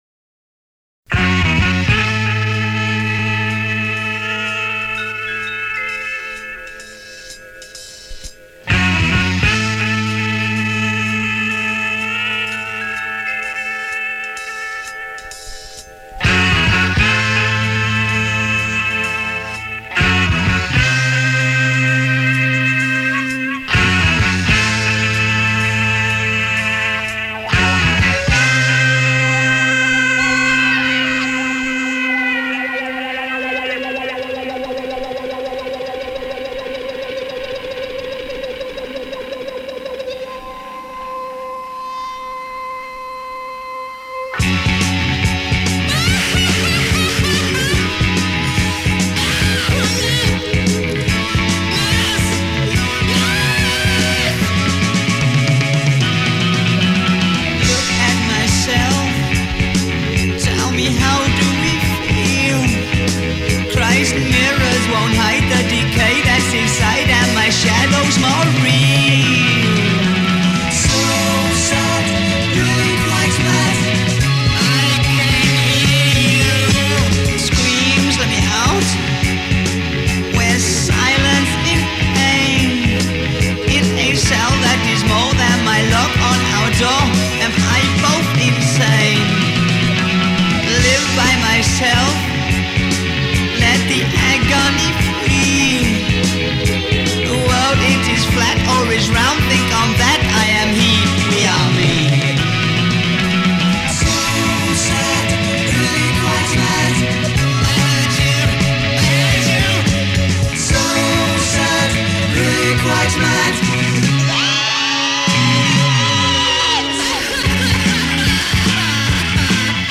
guitars / keyboards / vocals
bass / vocals / keyboards / violin
drums / percussion
electric saxes
Recorded 1974 at Escape Studios